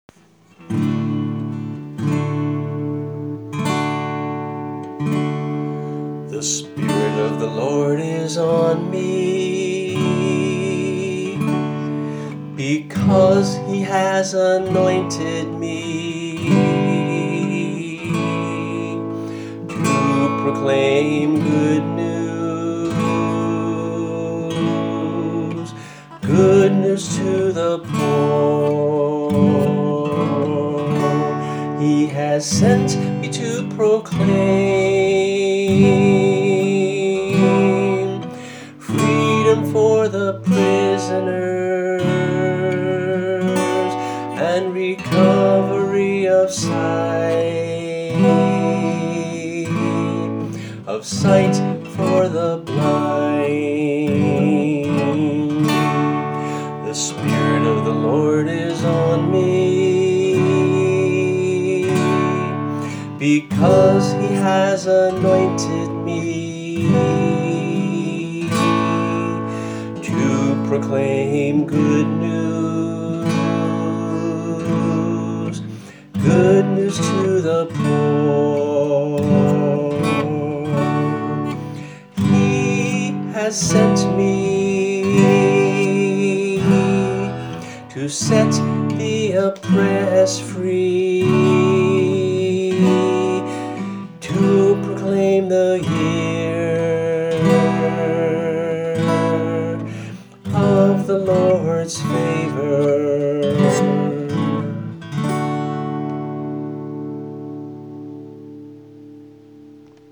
[MP3 - voice and guitar]
Luke 4:18-19 (NIV),  a 2-part echo song